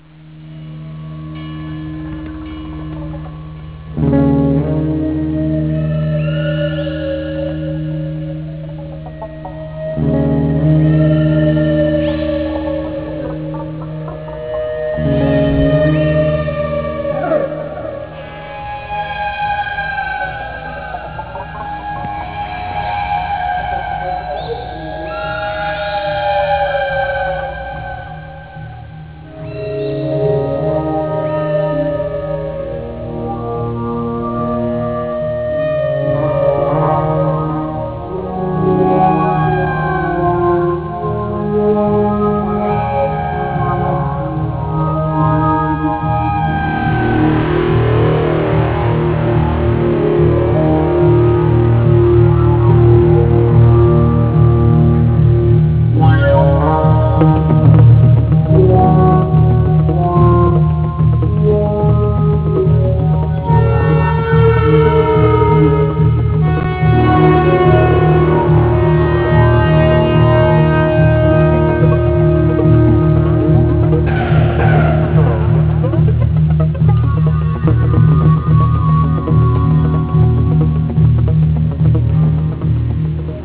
Guitar, Effects, Percussion
Didjereedoo